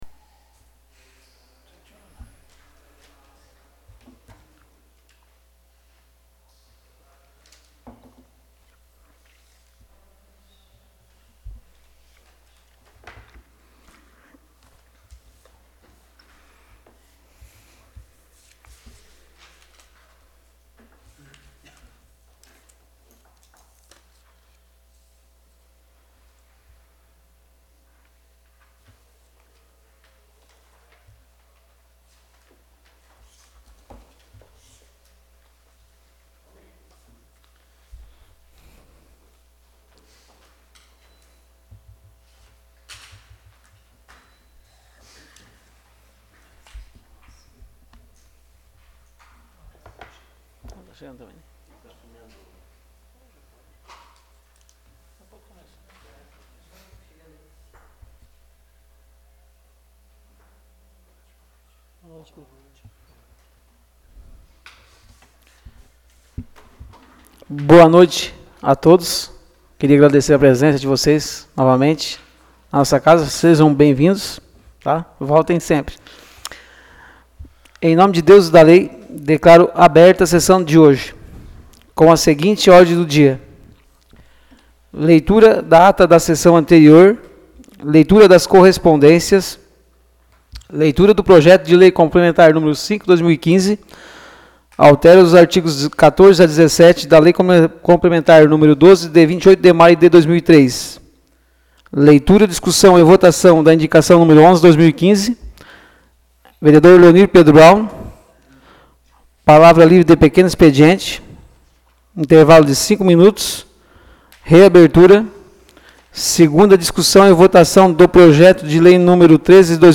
Áudio da Sessão Ordinária de 17 de agosto de 2015.